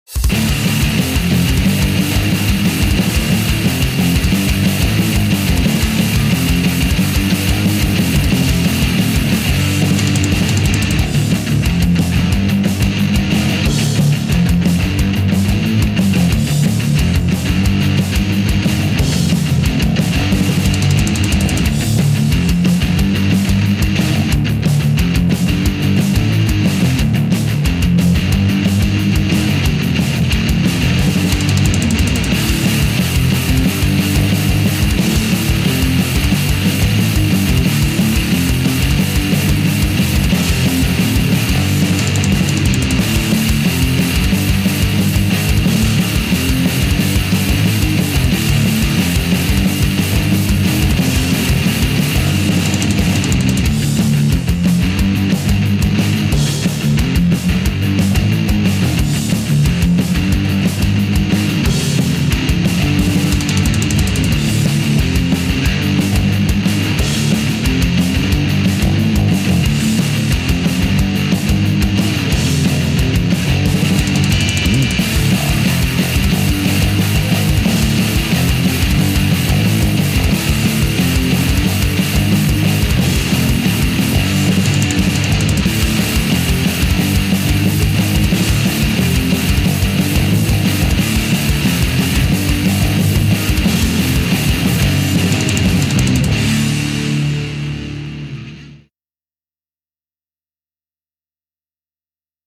【用途/イメージ】　アクションシーン　筋トレ動画　激しさ　荒々しさ
180BPM　バンドサウンド　はじけたシンプルロック